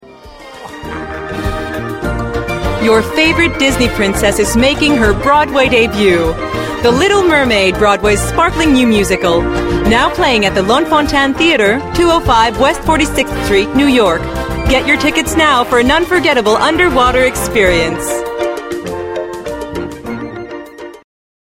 Publicité (The Little Mermaid) - ANG